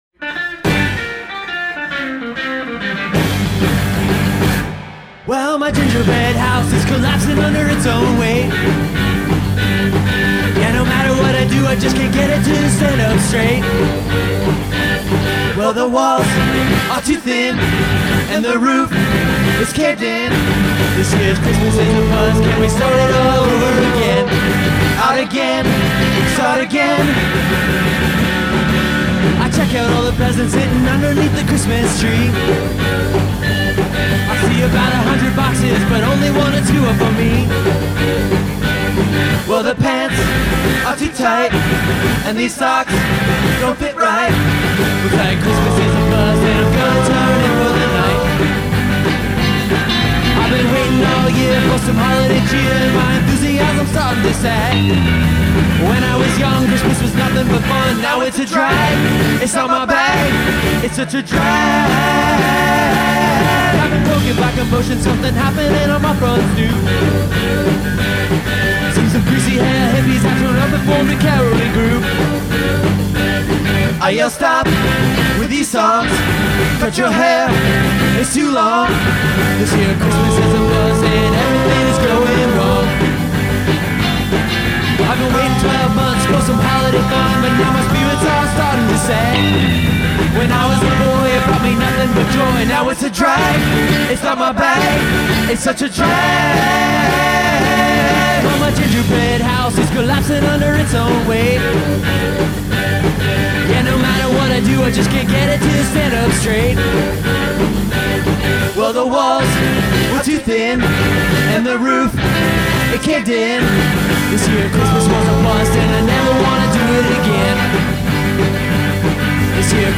Christmas-themed songs